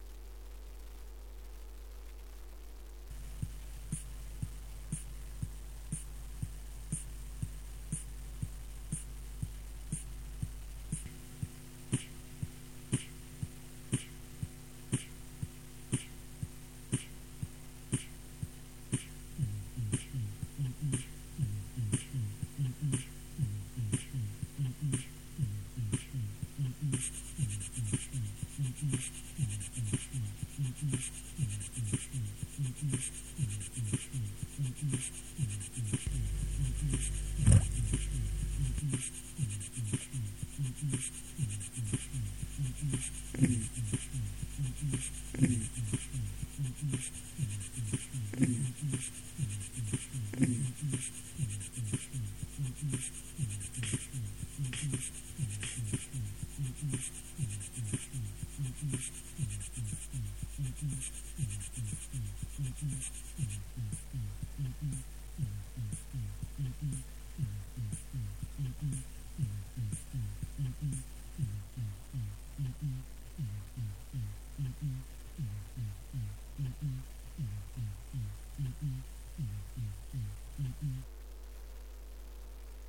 Записал на Ambiloop'е музычку х)))
молодец) получилось приятное такое техно)
очень тихо)